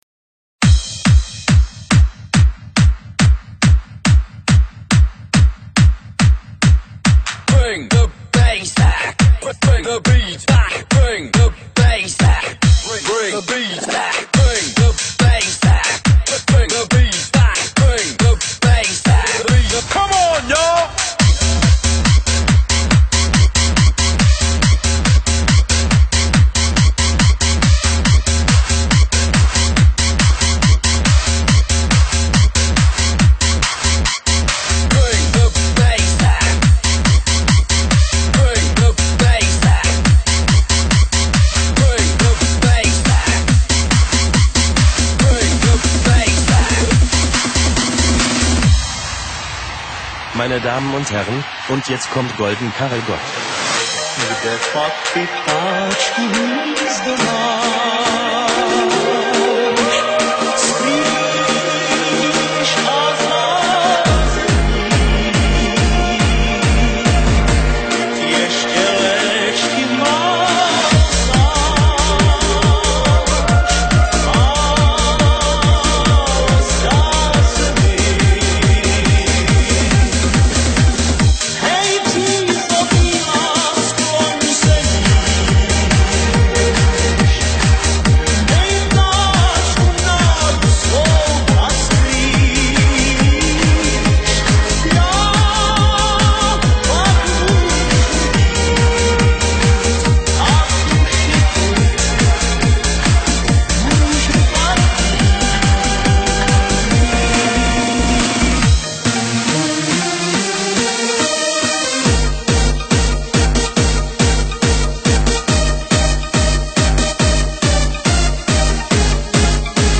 hands up remix